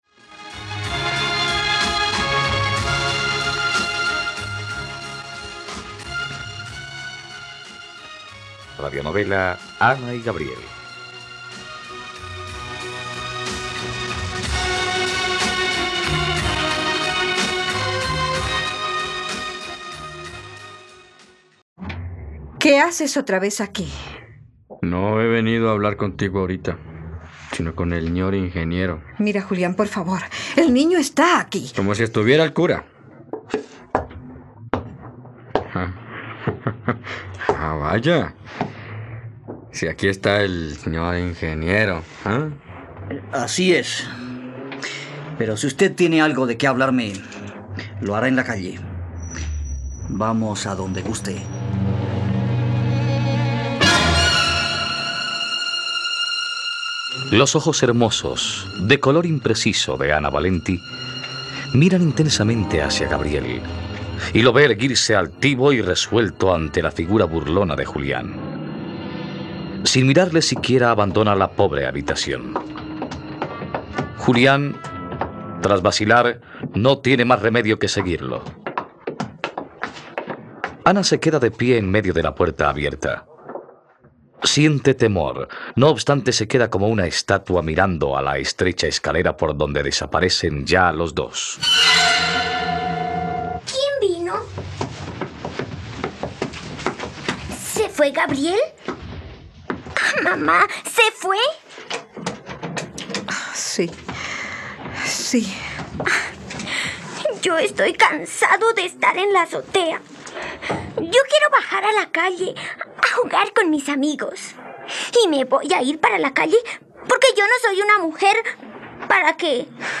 ..Radionovela. Escucha ahora el capítulo 60 de la historia de amor de Ana y Gabriel en la plataforma de streaming de los colombianos: RTVCPlay.